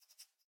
rabbit_idle2.ogg